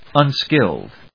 音節un・skilled 発音記号・読み方
/`ʌnskíld(米国英語)/